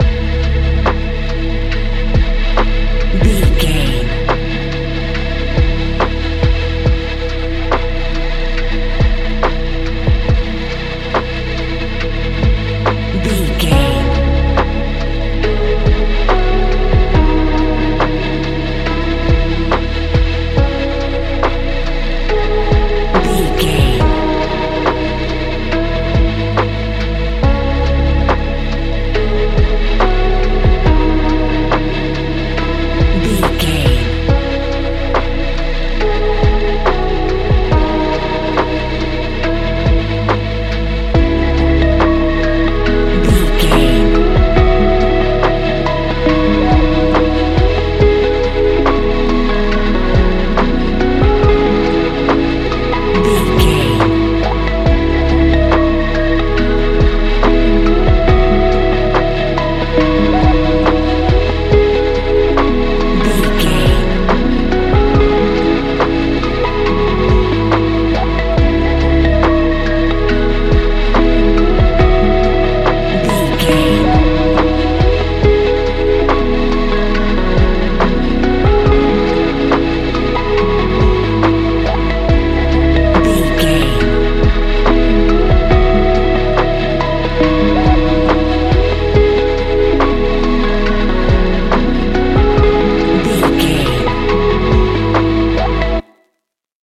Ionian/Major
C♯
chilled
laid back
Lounge
sparse
new age
chilled electronica
ambient
atmospheric
instrumentals